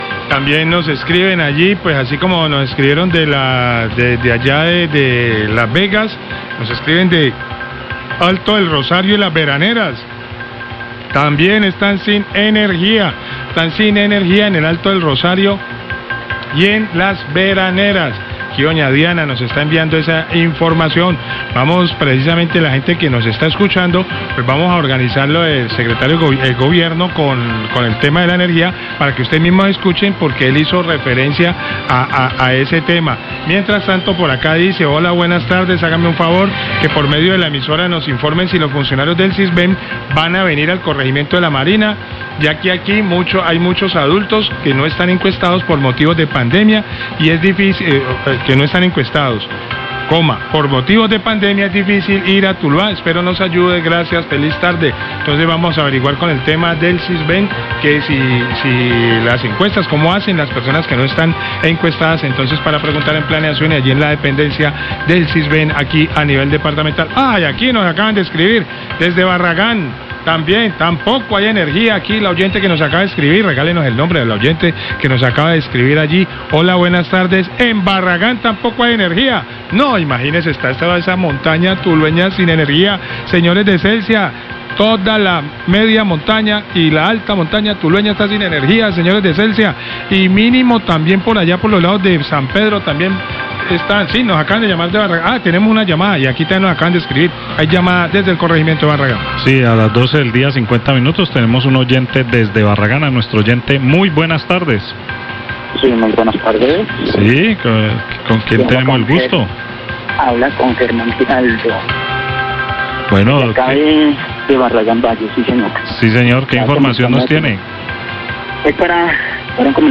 Radio
queja oyentes